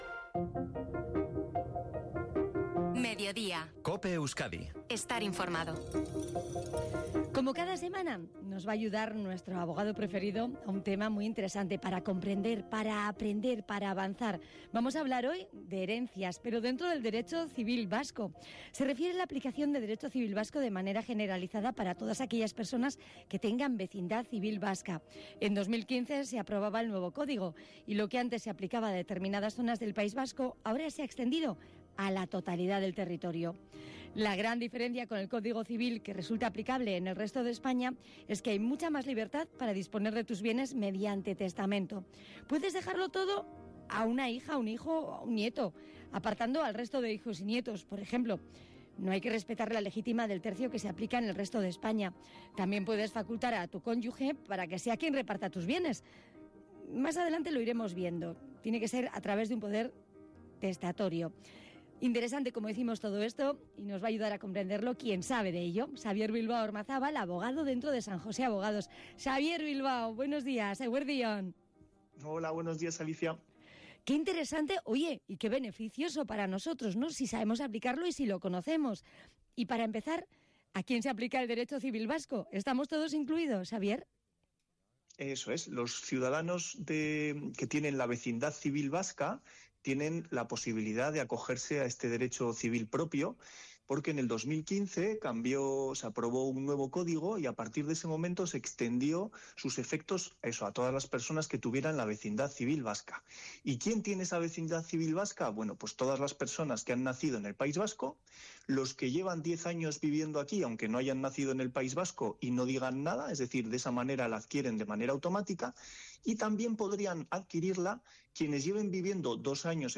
ENTREVISTA EN COPE SOBRE EL TESTAMENTO EN EL DERECHO FORAL VASCO - Despacho Abogados San Jose